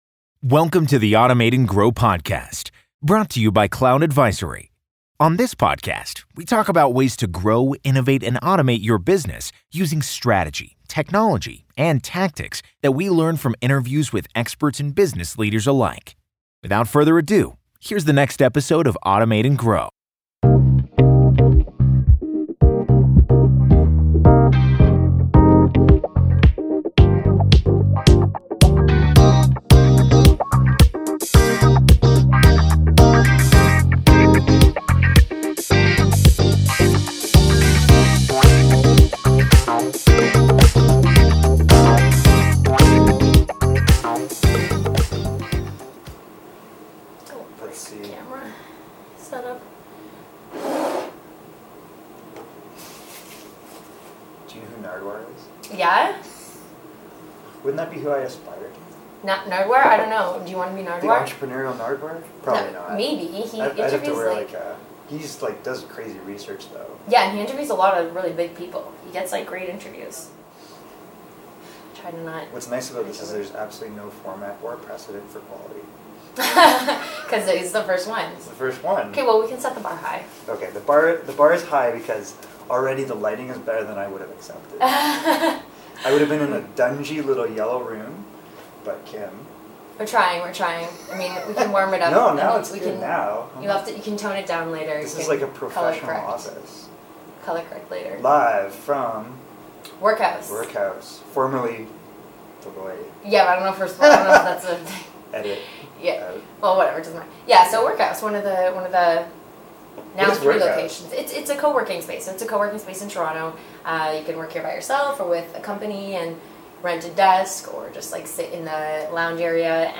Recorded at WorkHaus in Toronto